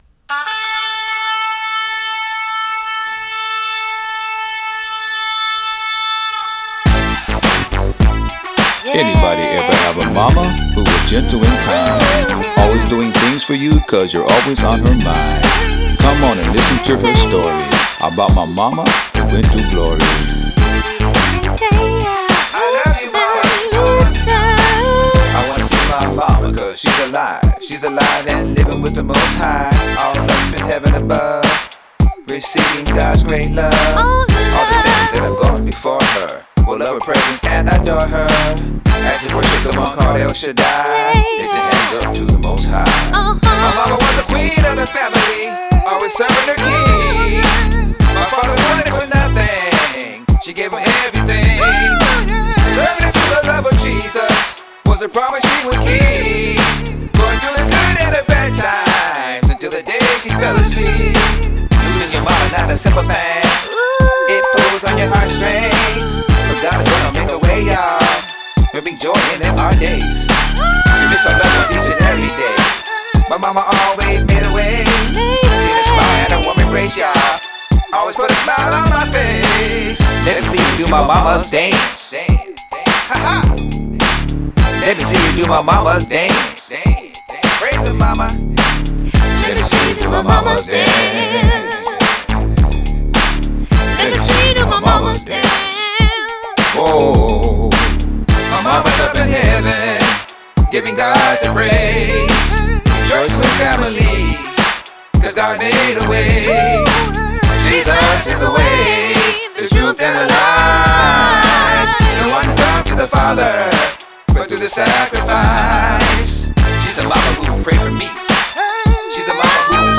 Christian
Vocals